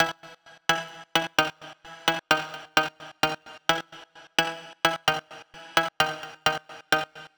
MB - Loop 10 - 65BPM.wav